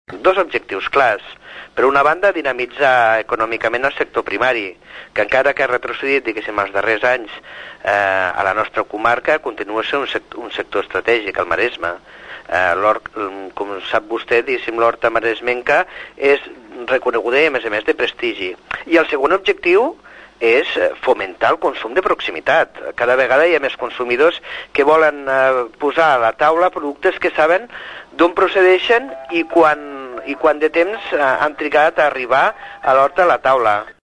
Miquel Àngel Martínez, és el president del consell comarcal.